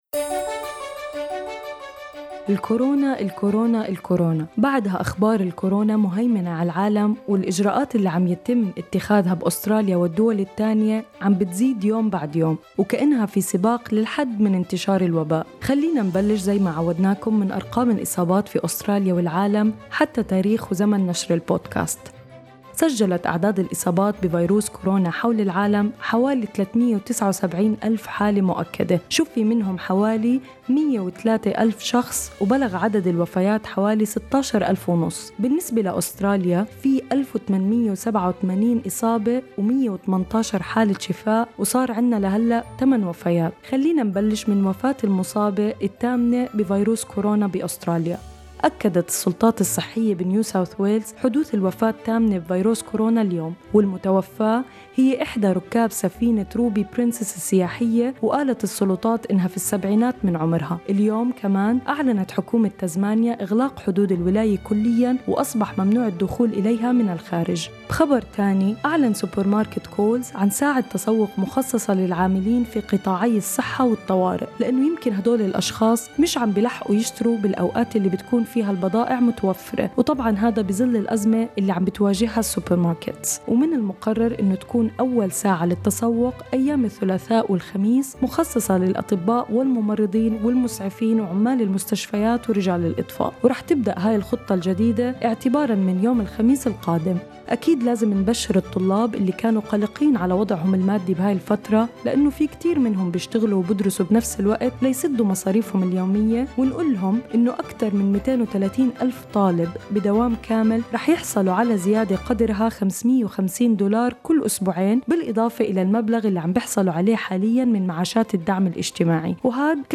أخبار كورونا اليوم 24/03/2020
today_corona_with_music_mixdown.mp3